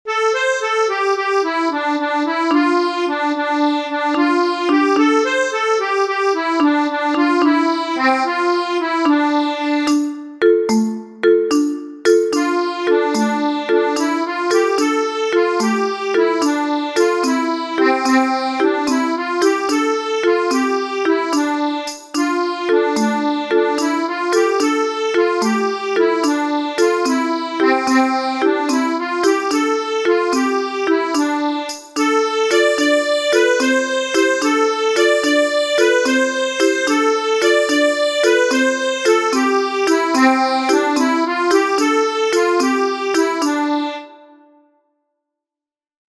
-The instrumental percussion accompaniment of this piece is organized into rhythmic motifs.
The tune has a 9/8 time signature and is usually played at a fast tempo, which means a challenge for singers. Today, it is considered a representative piece of Irish folk music.